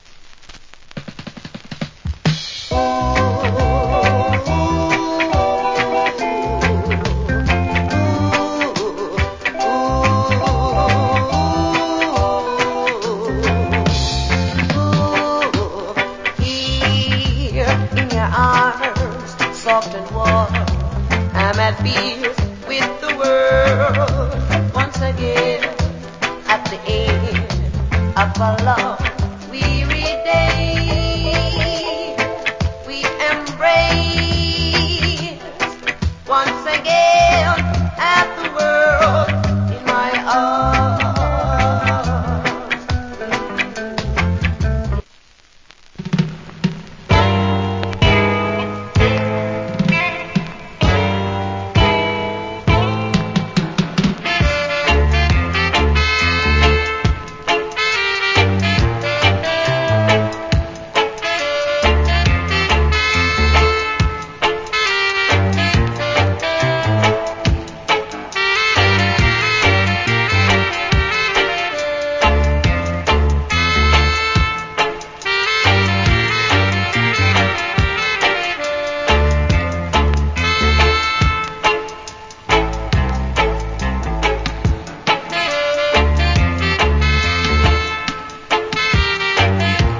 Cool Roots Vocal.